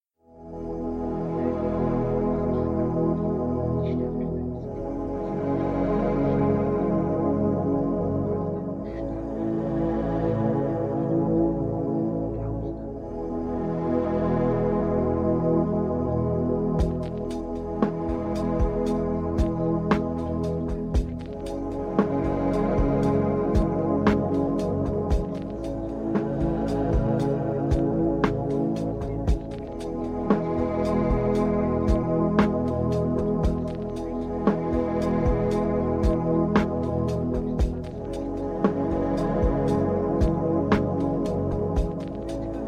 Starker Glaube 1A Subliminal Hörprobe
starker-Ggaube-1a-subliminal-hörprobe.mp3